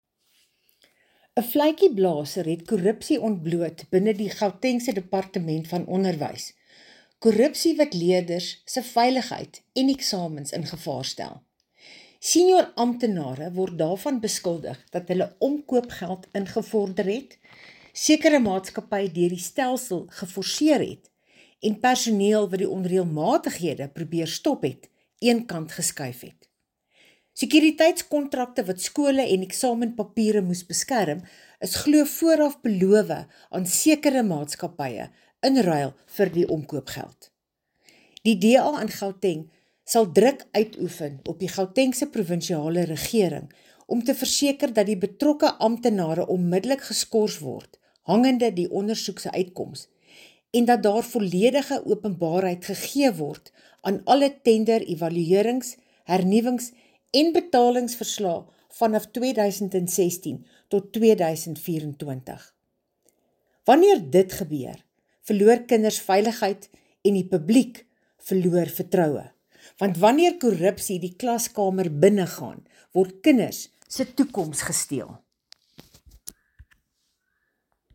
Afrikaans soundbites by Bronwynn Englebrecht MPL.